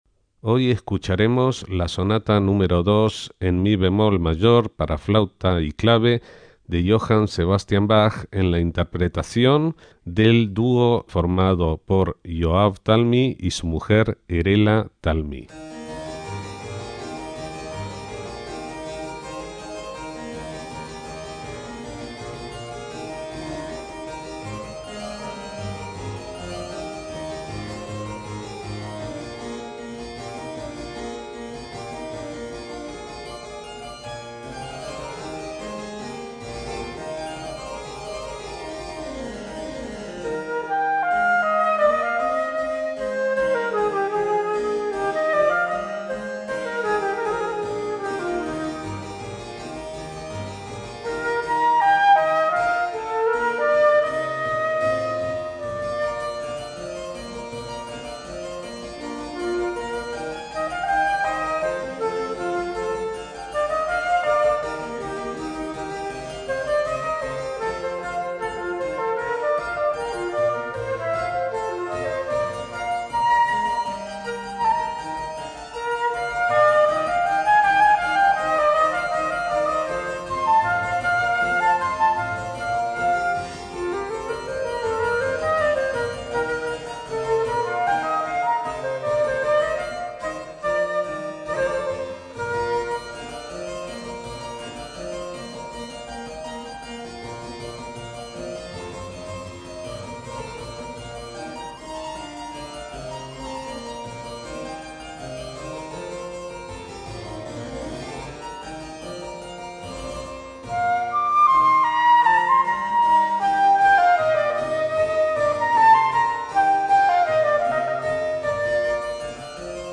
MÚSICA CLÁSICA
en este caso será la Sonata en mi bemol mayor para flauta y clave BMW1031 de Johann Sebastian Bach